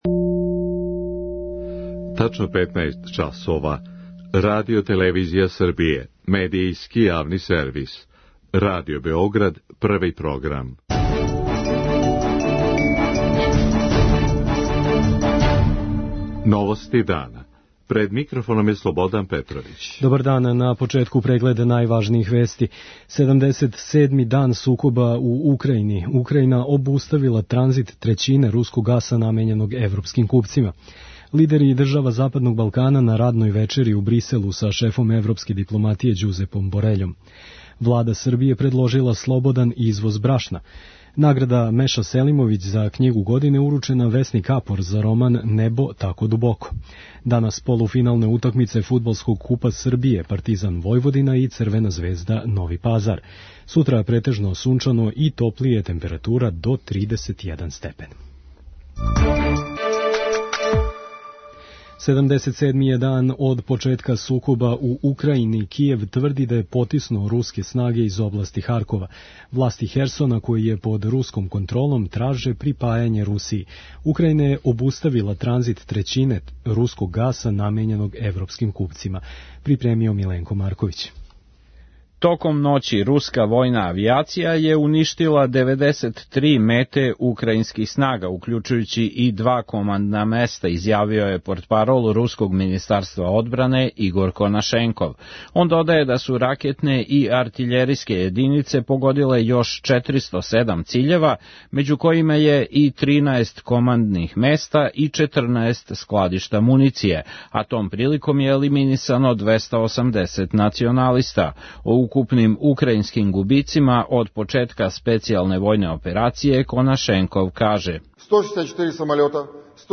Седамдесет седми дан сукоба у Украјини | Радио Београд 1 | РТС